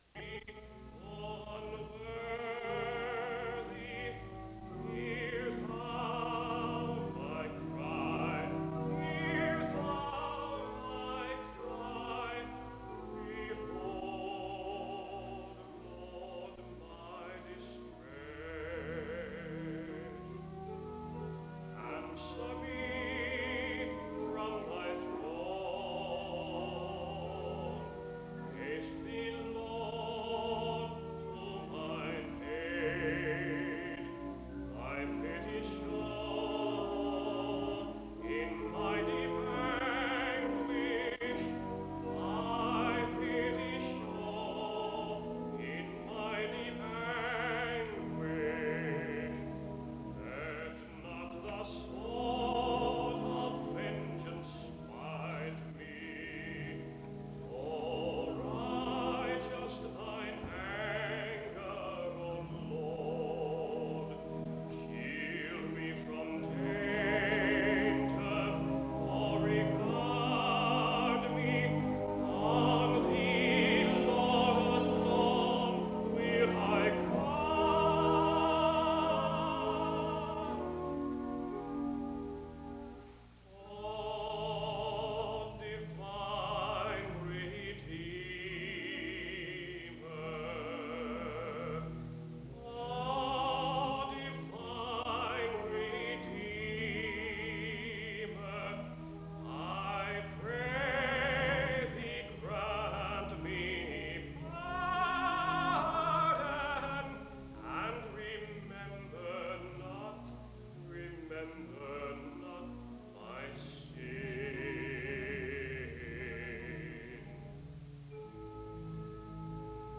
The service begins with music from 0:00-5:13. There is scripture reading and prayer from 5:27-7:24. “Joy To the World” is sung from 7:30-10:30. There is an introduction to the speaker from 10:38-16:24.
In Collection: SEBTS Chapel and Special Event Recordings SEBTS Chapel and Special Event Recordings